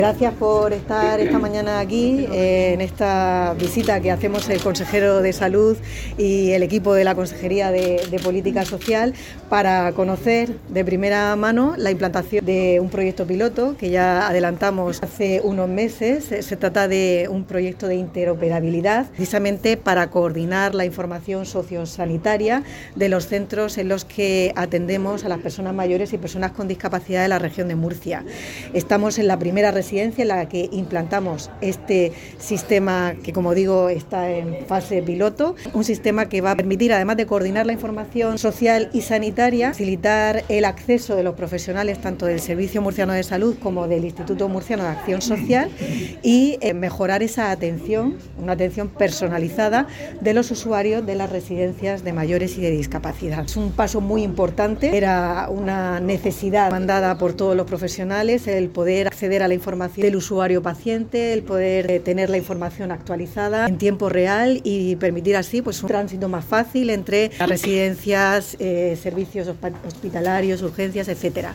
consejera de Política Social, Familias e Igualdad, Conchita Ruiz sobre la implantación del proyecto piloto de la Comisión Sociosanitaria que mejora la seguridad y asistencia de los usuarios de las residencias